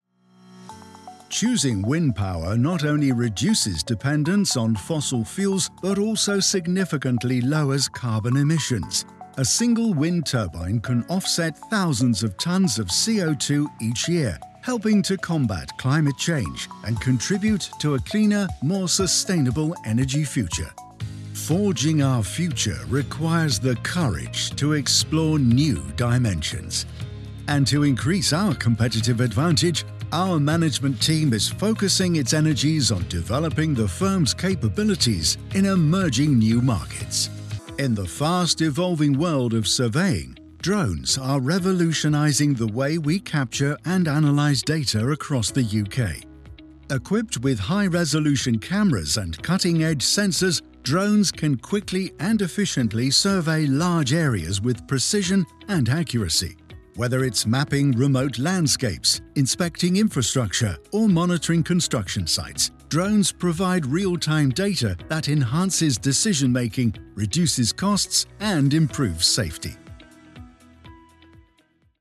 Male
British English (Native)
Assured, Authoritative, Character, Confident, Corporate, Engaging, Friendly, Natural, Smooth, Warm, Versatile
Microphone: Rode NT1a